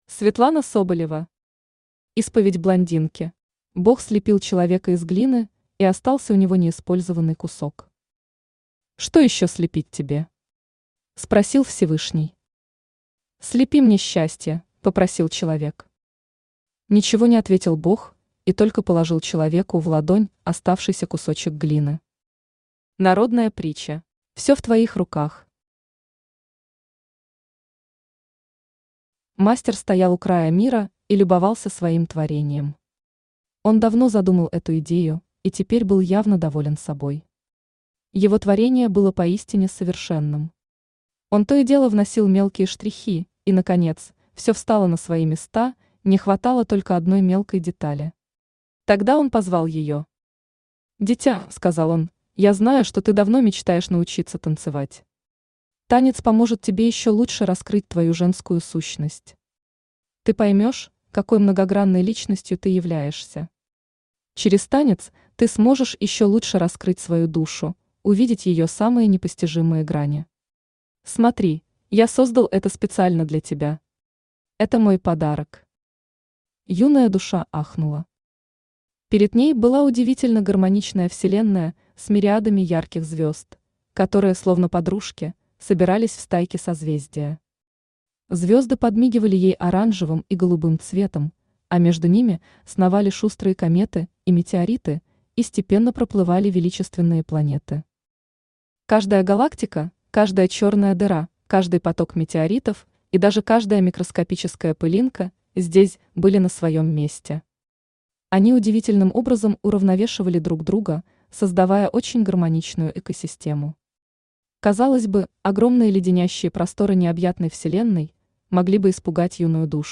Аудиокнига Исповедь блондинки | Библиотека аудиокниг
Aудиокнига Исповедь блондинки Автор Светлана Соболева Читает аудиокнигу Авточтец ЛитРес.